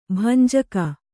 ♪ bhanjaka